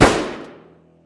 Sound Buttons: Sound Buttons View : Blacksad- Gunshot